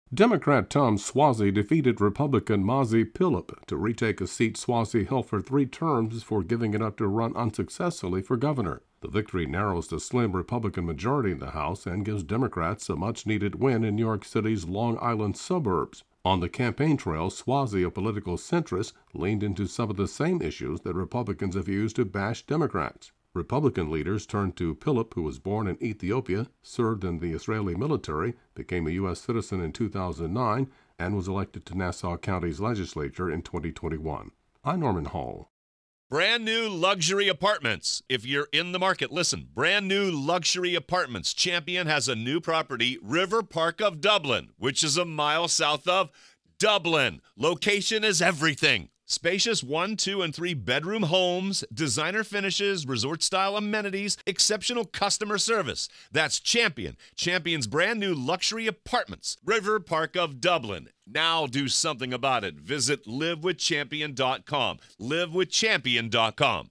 reports on the special election in New York for the U.S. House seat left vacant when George Santos was expelled.